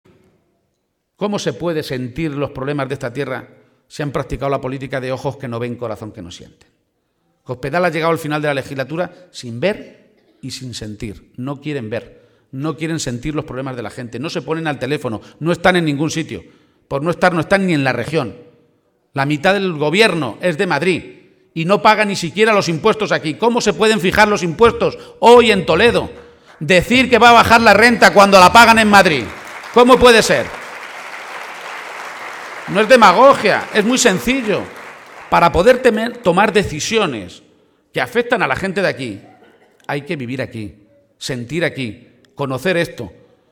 Momento del acto público en Mora